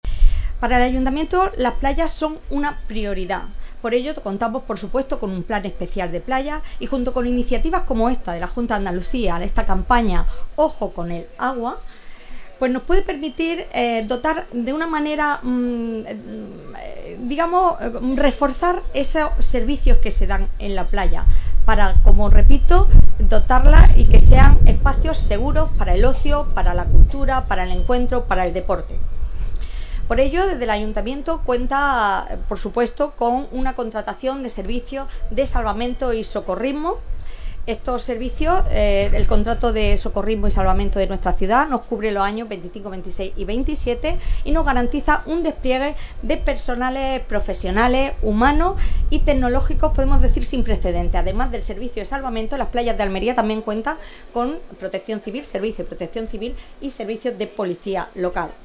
La concejala de Obras Públicas, Mantenimiento, Accesibilidad y Economía Azul del Ayuntamiento de Almería, Sacra Sánchez, y la delegada del Gobierno de la Junta de Andalucía en Almería, Aránzazu Martín, han presentado en la playa de El Palmeral, en el Zapillo, la nueva campaña de la Agencia de Emergencia de Andalucía (EMA) para prevenir ahogamientos y accidentes en el medio acuático este verano, que lleva por lema ‘Ojo con el agua’ y que se emitirá en televisión, radio, prensa y redes sociales.